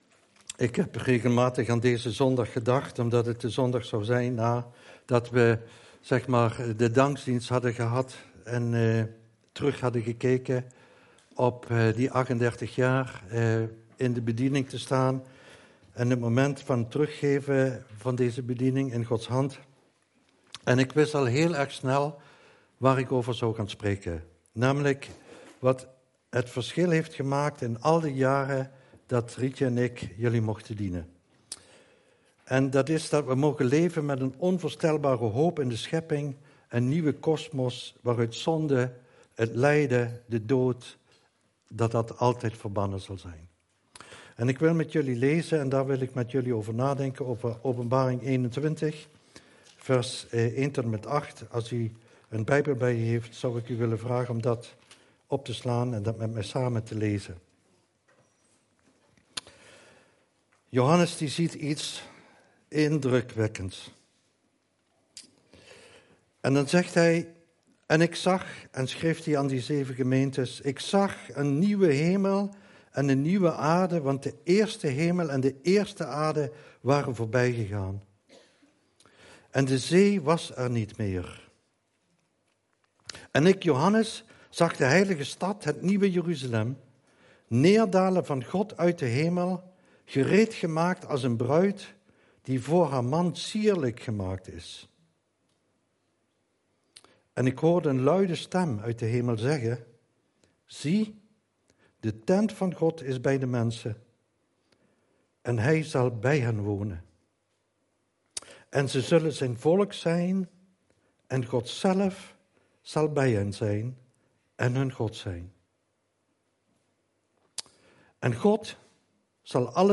Preken – Christengemeente Midden-Limburg